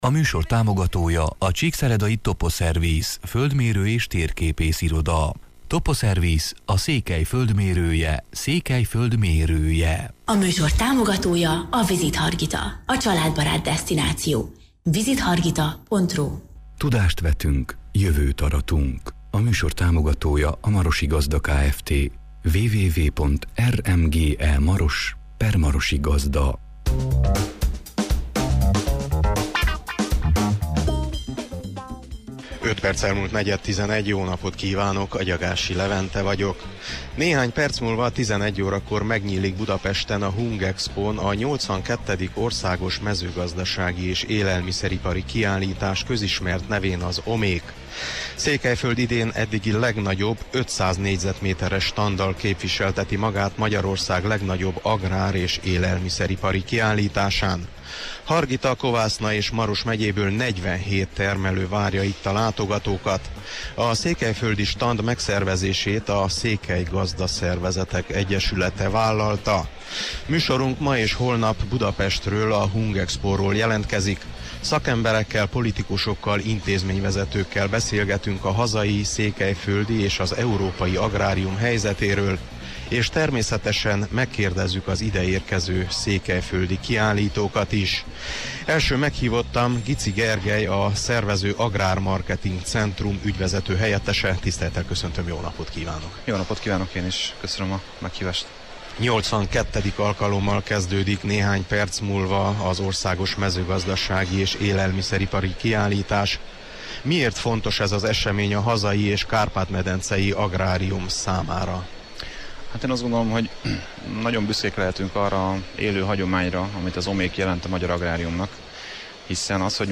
Műsorunk Budapestről, a Hungexpo-ról jelentkezett. Szakemberekkel, politikusokkal, intézményvezetőkkel beszélgettünk a hazai, székelyföldi és az európai agrárium helyzetéről, és természetesen megkérdeztük az ide érkező székelyföldi kiállítókat is.